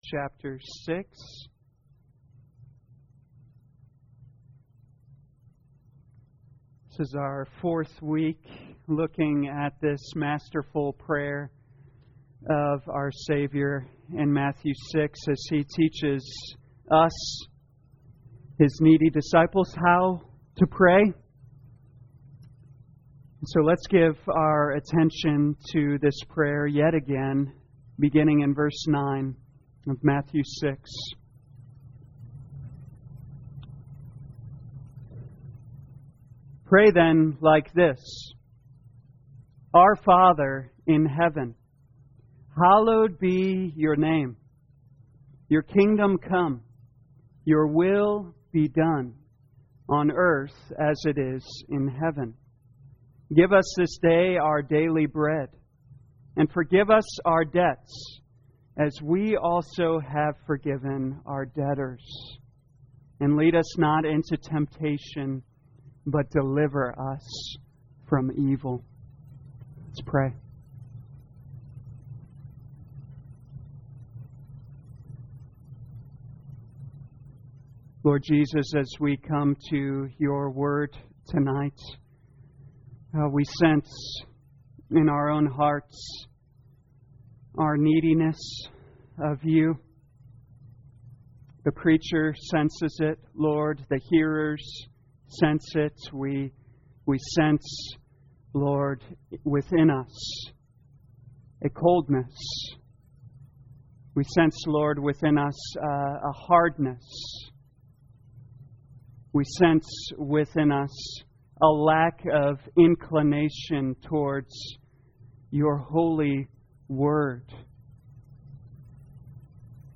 2021 Matthew Prayer Evening Service Download